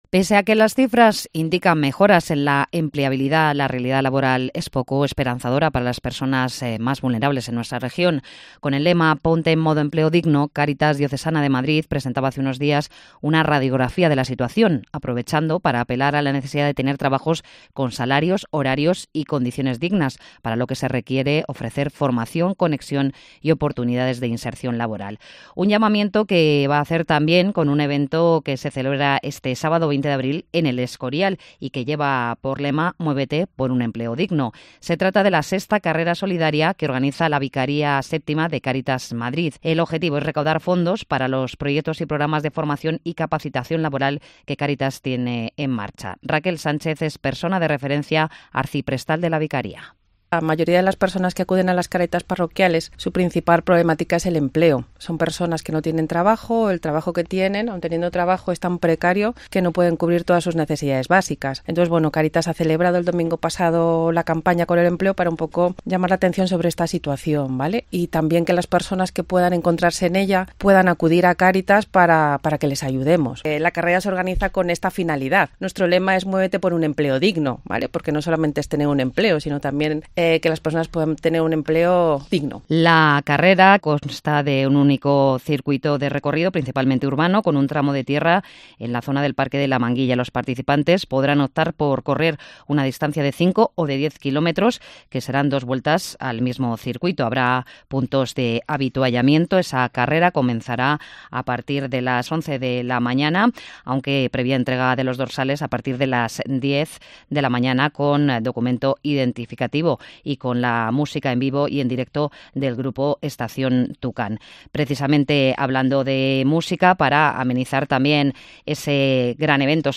en su paso por los micrófonos de COPE de la SIERRA.